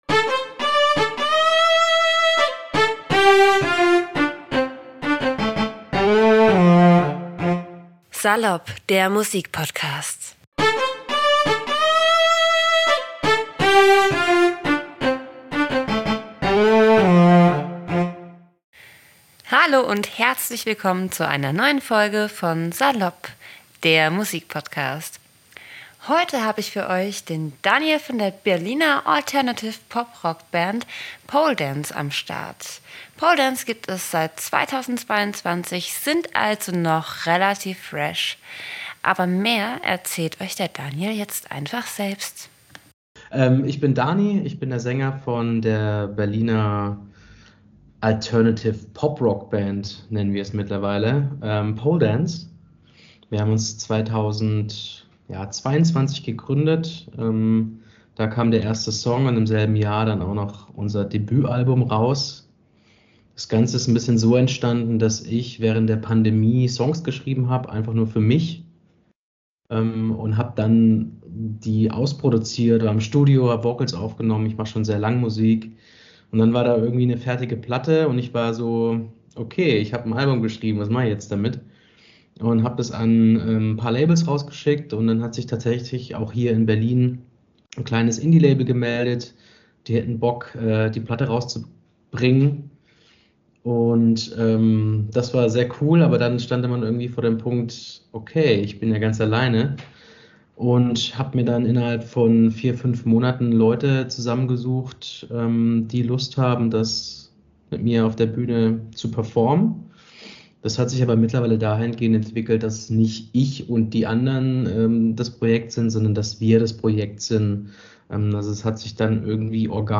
interview-mit-poledance-unsere-texte-sind-wie-ein-tagebuch-mmp.mp3